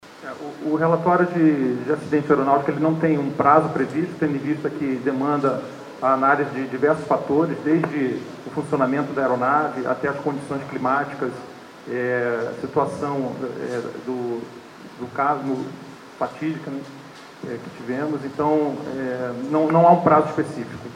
A informação foi confirmada pelo major-brigadeiro, David Almeida Alcoforado, titular do Sétimo Comando Aéreo Regional (VII Comar), em coletiva de imprensa realizada nesse domingo (17) após a chegada dos corpos das vítimas à capital.